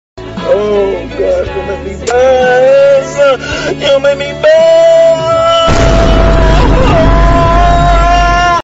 Buussss Sound Buussss Sound sound button Buussss Sound sound effect Buussss Sound soundboard Get Ringtones Download Mp3 Notification Sound